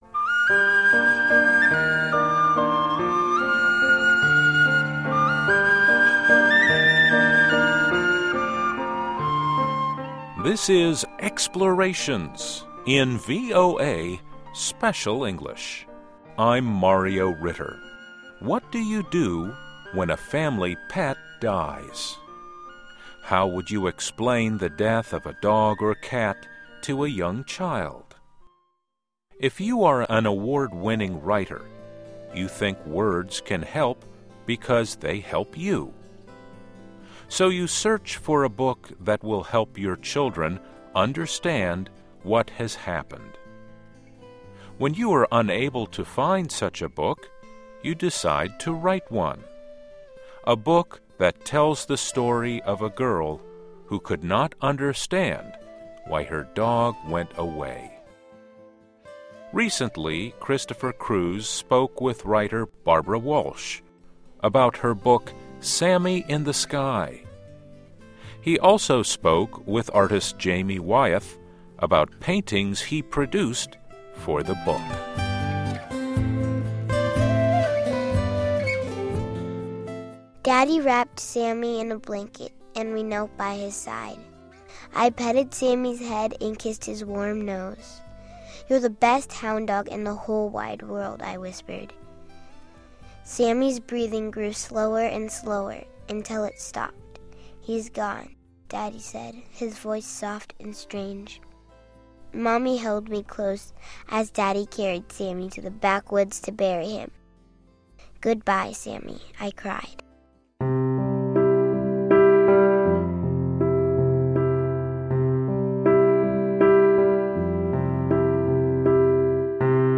He also spoke with artist Jamie Wyeth about paintings he produced for the book.